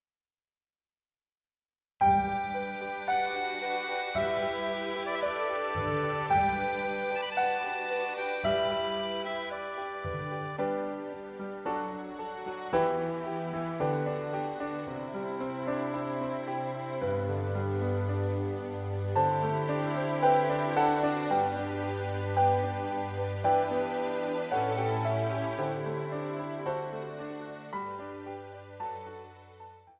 Instrumental Solos Cello